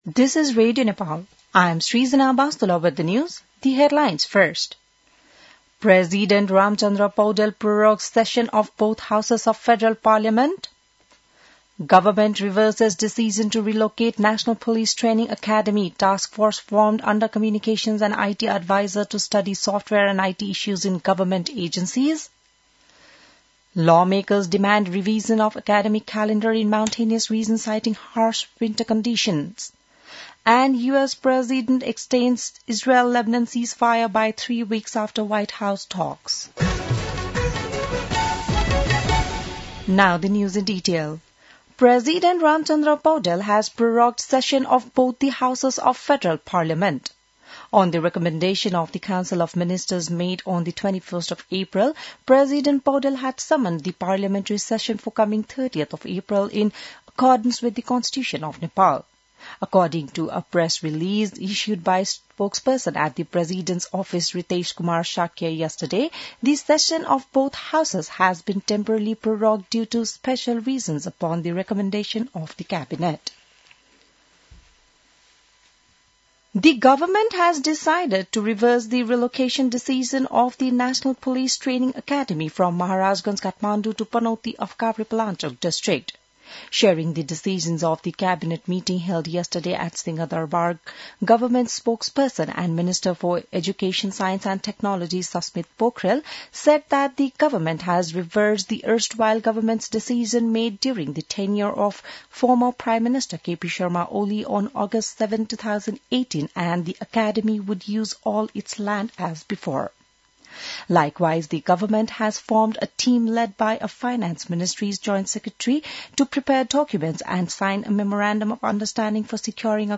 बिहान ८ बजेको अङ्ग्रेजी समाचार : ११ वैशाख , २०८३